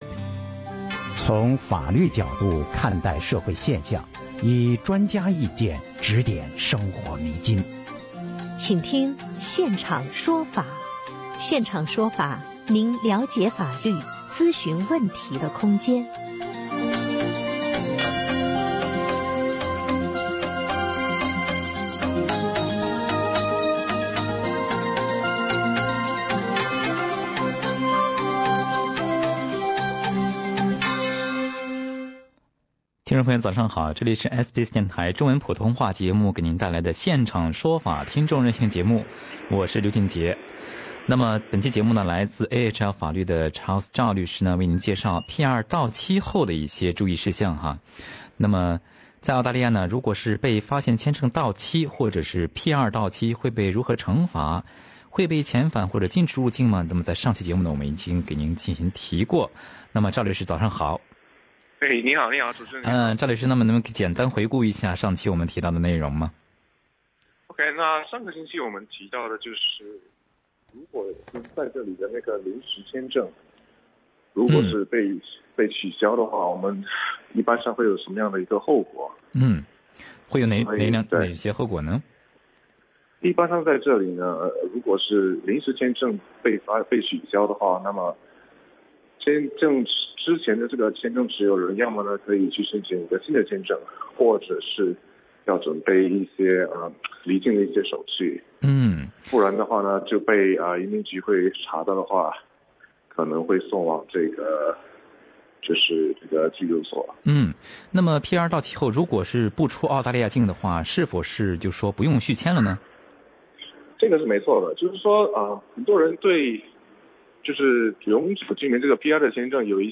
有不少听众朋友咨询了签证相关的问题。